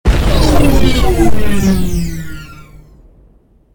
railgunlaunch.ogg